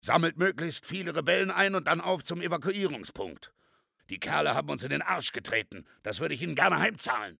In addition to recurring voices from the previous instalments, actor Ben Becker joins the fray in the role of protagonist Soap MacTavish, rendering the gripping story even more genuine and frenzied with his brilliant performance as the game's hero.